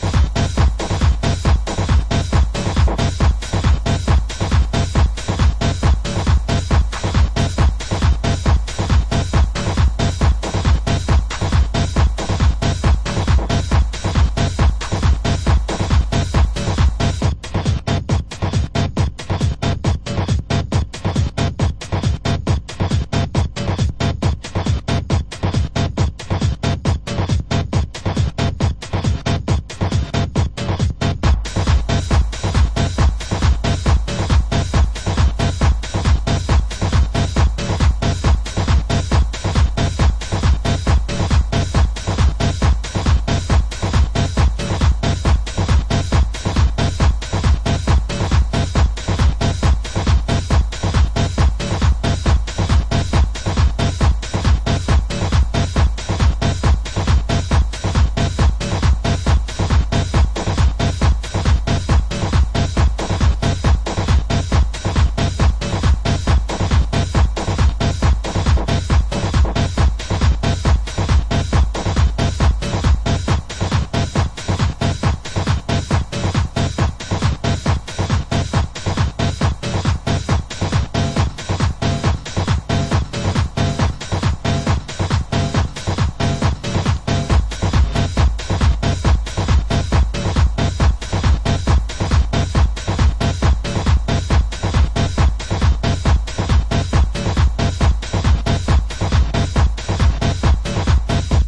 Techno!!...and some funk samples.